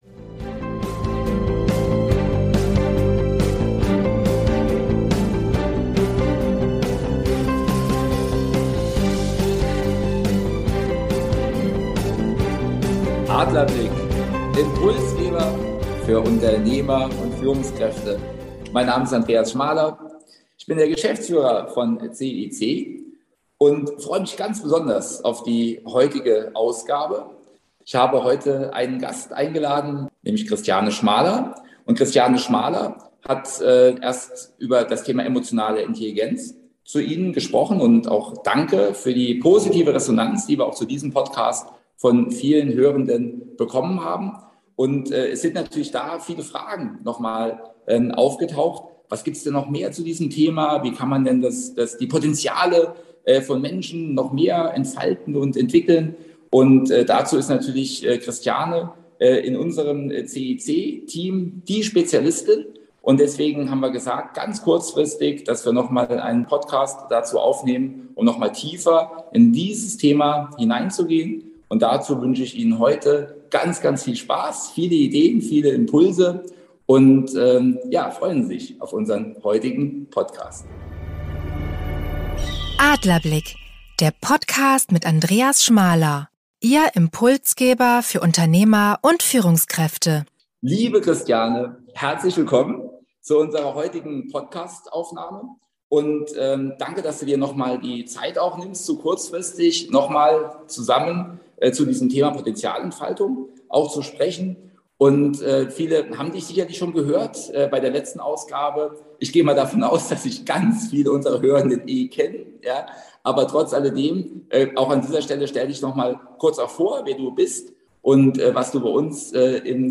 erklärt in einem spannenden Interview